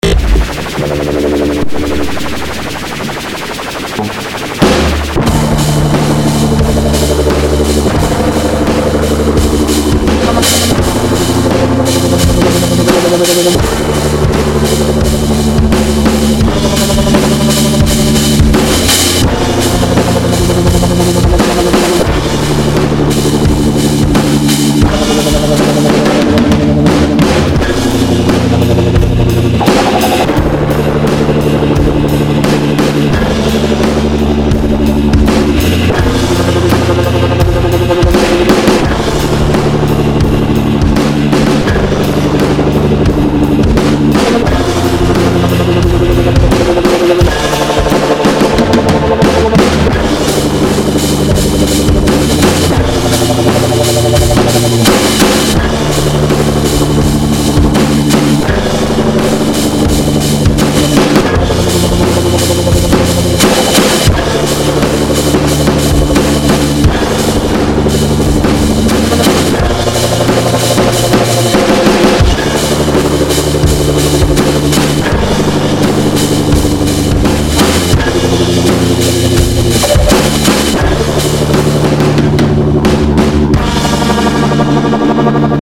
По отдельности все звучит вполне удовлетворительно, но при игре на обоих инструментах будто бы снижается громкость обоих, и ударные как бы заглушаются гитарой. То есть если высокие частоты еще слышно, то средние и низкие уже проседают очень сильно.
там слышно, как атака гитары глушит ударные, понять не могу в чем проблема
Пиздос, нихуя не разобрать вообще.